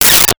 Space Gun 11
Space Gun 11.wav